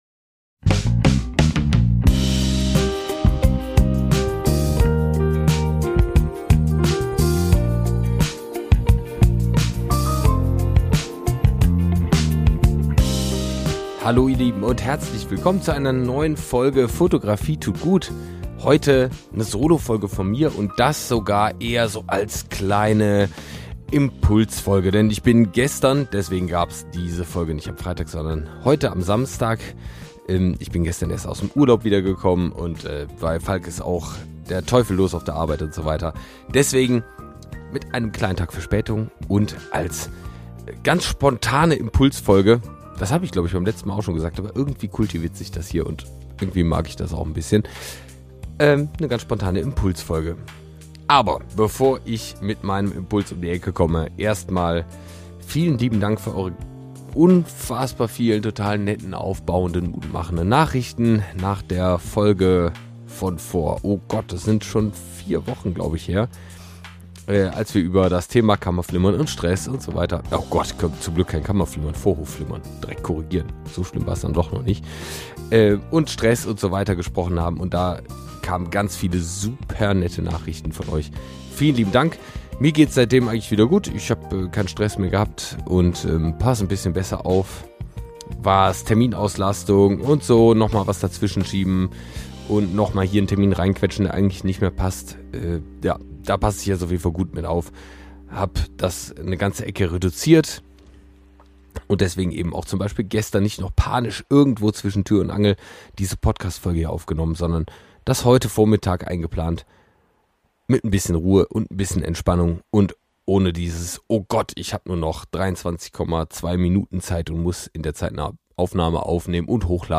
Solo Episode